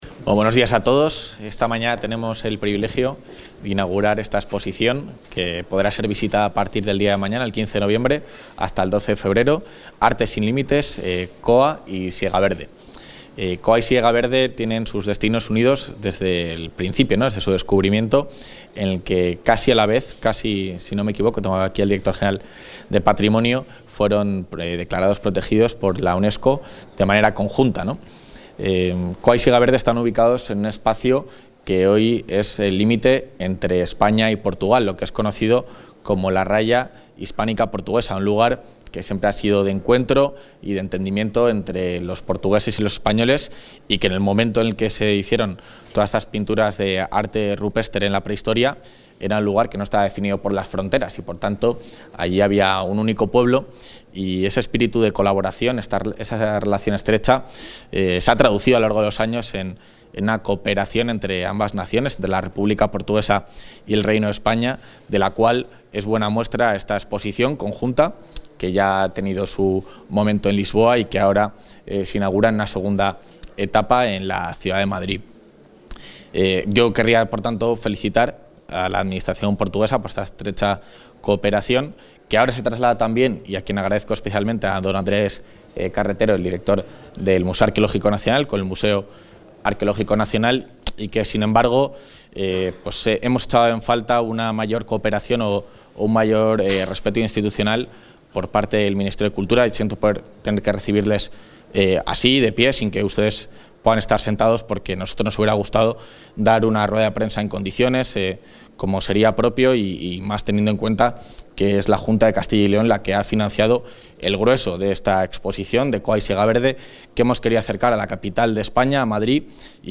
El vicepresidente de la Junta de Castilla y León, Juan García-Gallardo, ha inaugurado en el Museo Arqueológico Nacional (MAN) la...
Intervención del vicepresidente de la Junta.